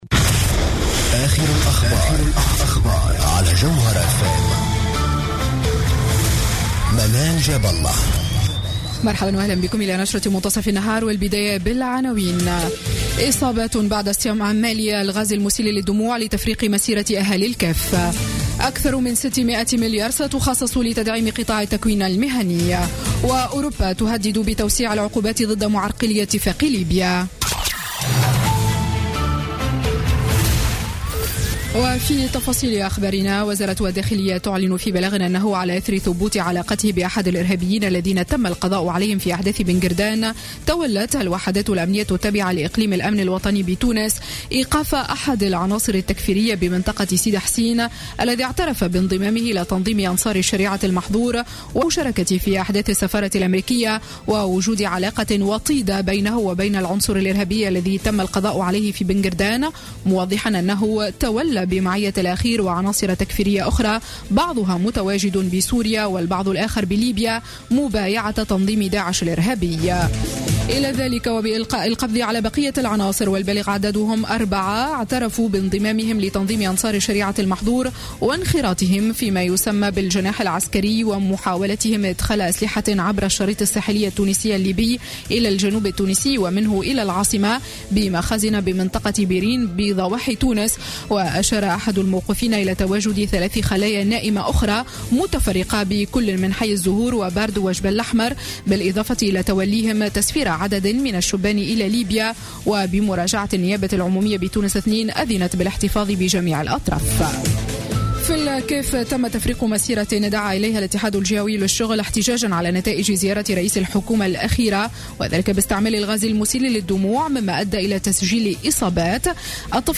نشرة أخبار منتصف النهار ليوم الثلاثاء 19 أفريل 2016